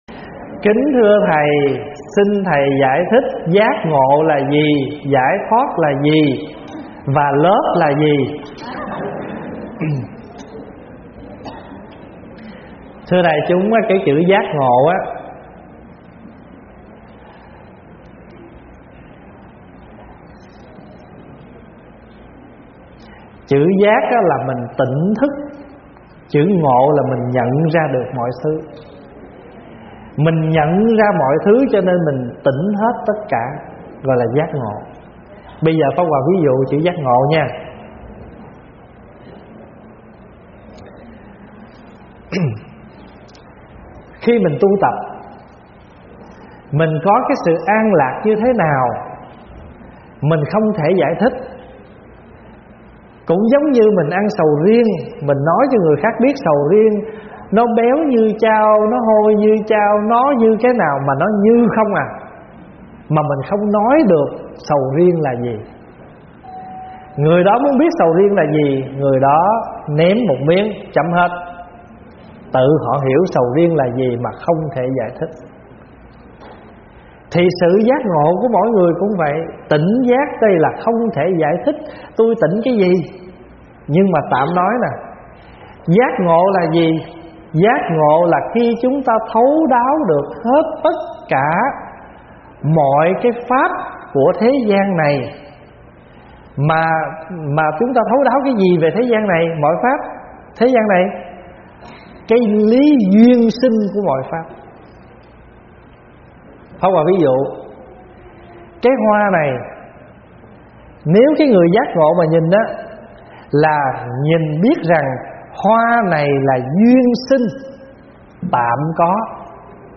Mời quý phật tử nghe vấn đáp Giác Ngộ và Giải Thoát - ĐĐ. Thích Pháp Hòa giảng
Mp3 Thuyết Pháp     Thuyết Pháp Thích Pháp Hòa     Vấn đáp Phật Pháp